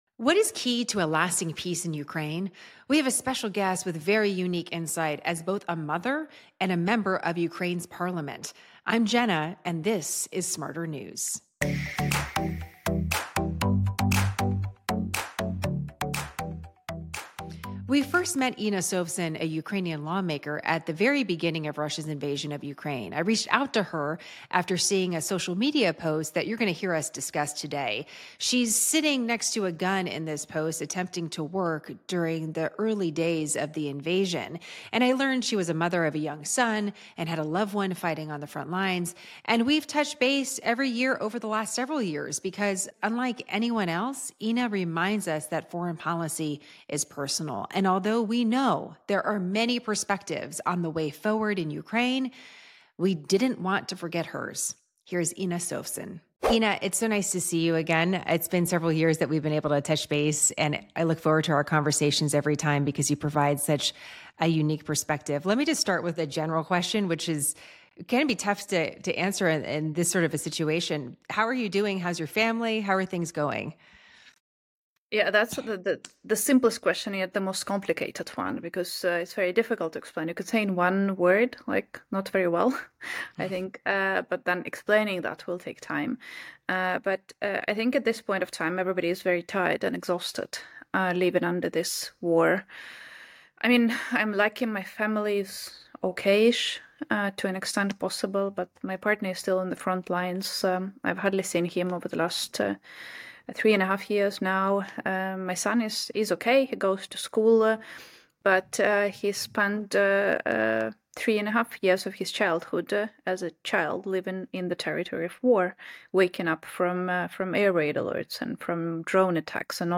With Ukrainian lawmaker, Inna Sovsun, join us from Kyiv.